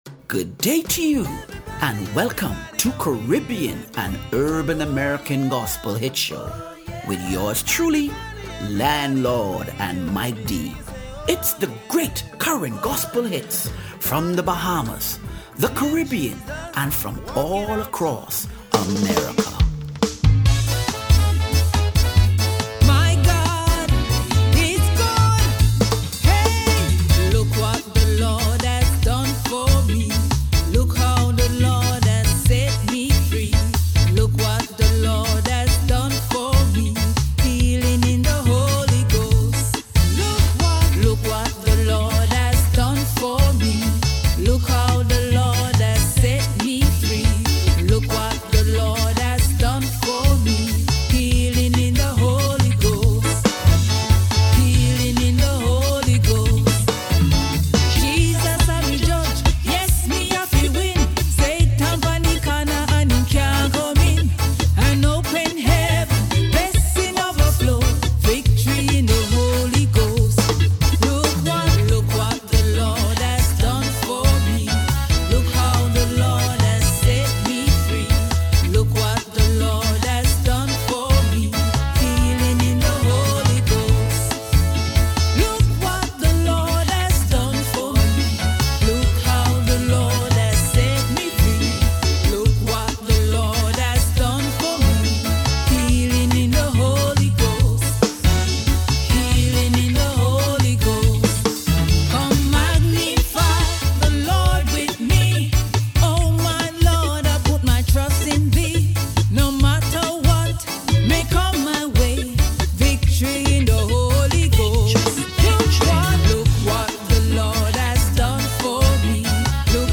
Caribbean and Urban American Gospel Hits - October 26 2025